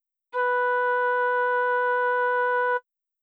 Si jouée à la flûte.
flute_si3.wav